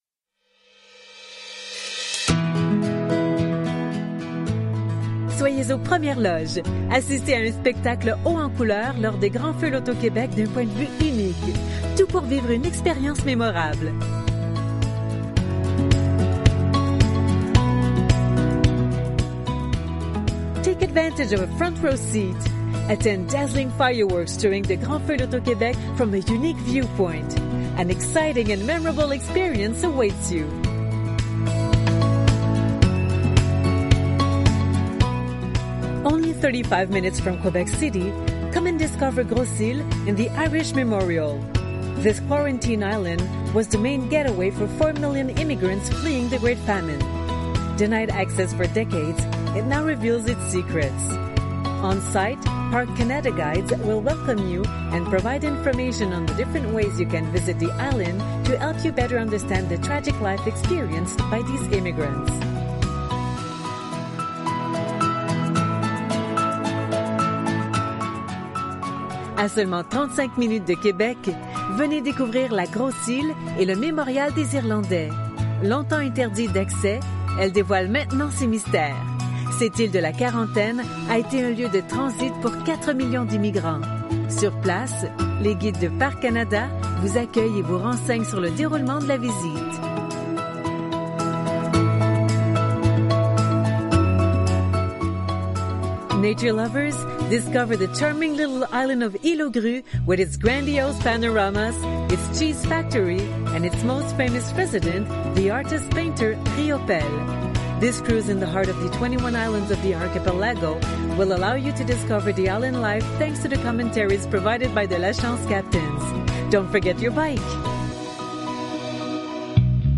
Comprend un message d’attente, la rédaction, la narration et le montage musical (pas de contrat et pas de versement mensuel)